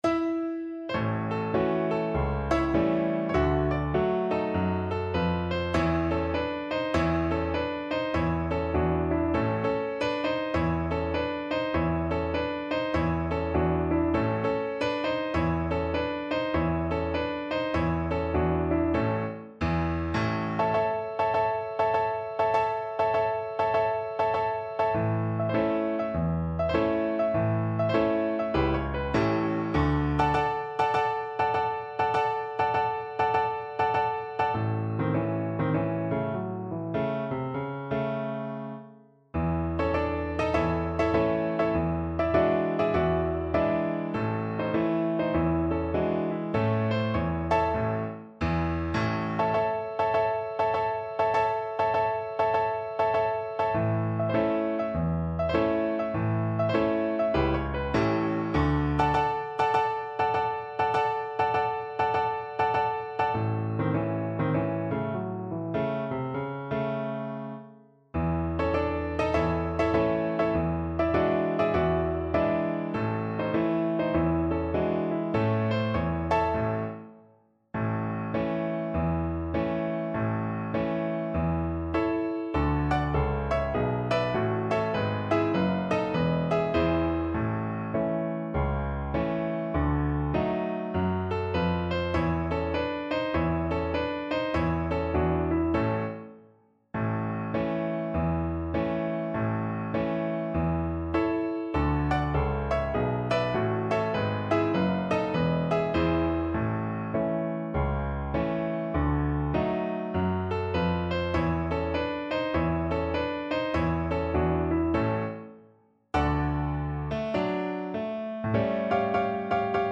Jazz (View more Jazz Violin Music)